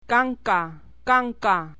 titi   . k